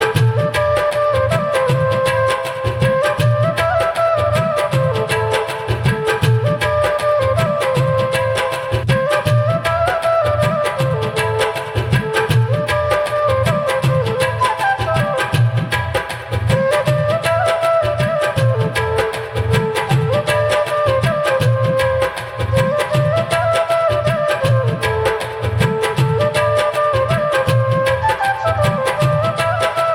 Flute Ringtone.